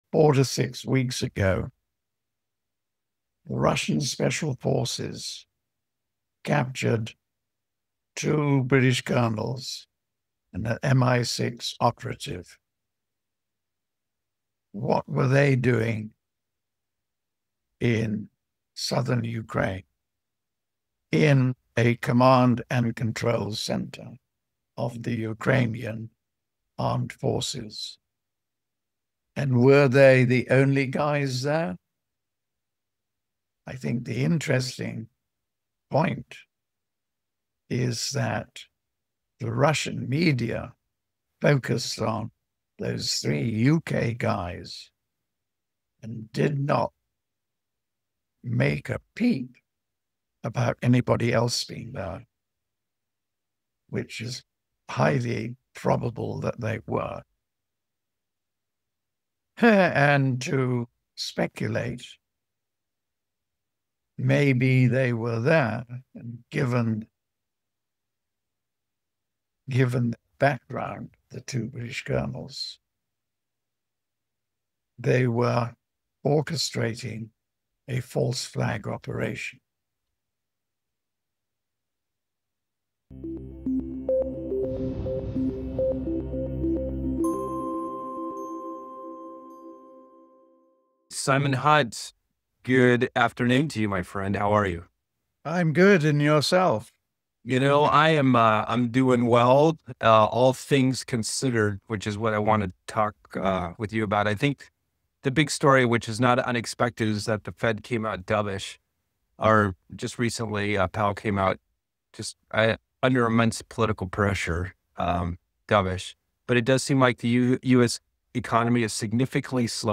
Global Economic Outlook: Europe’s Destiny, BRICS, and Potential Conflicts Interview